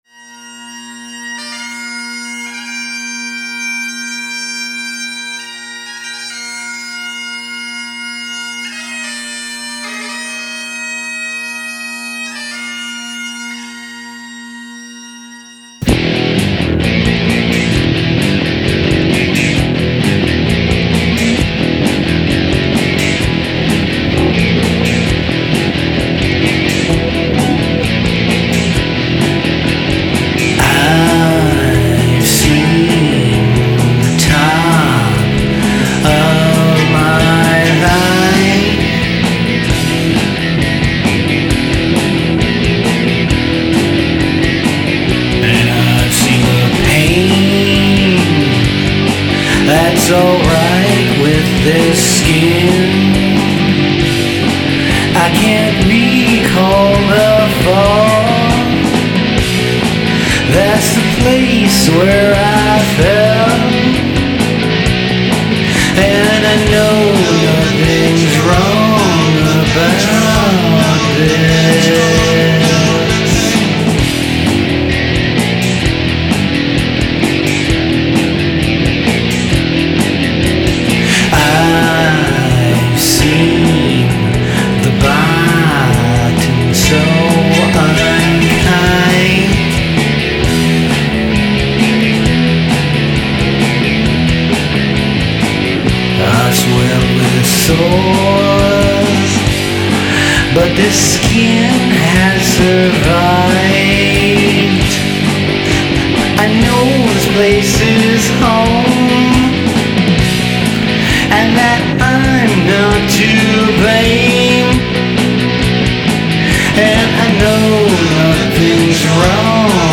Guitars and vocals.
Drums, percussion, backup vocals.
Bass guitar.
***SUPERCHAGED 60s PSYCHEDELIC